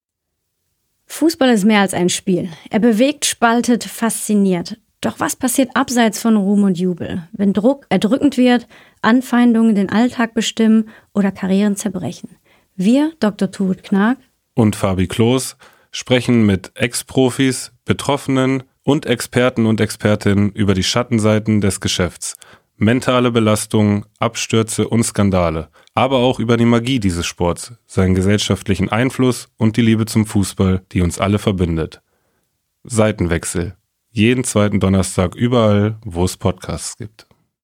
Ex-Nationalspielerin Dr. Turid Knaak und Arminia-Legende Fabi Klos weKKseln die Seiten und sprechen mit (Ex-) Profis, Betroffenen und Expert*innen über die Schattenseiten des Geschäfts: psychische Belastungen, Abstürze, Skandale, massive Anfeindungen und menschliche Tragödien. Aber auch über die Magie dieses Spiels, seinen gesellschaftlichen Impact und die bedingungslose Liebe zum runden Leder.